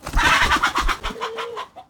CosmicRageSounds / ogg / general / combat / creatures / CHİCKEN / he / die1.ogg
die1.ogg